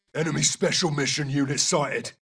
voices/eng/adult/male/Ghost_MW2_22khz.wav
Ghost_MW2_22khz.wav